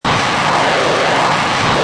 Download Rocket sound effect for free.
Rocket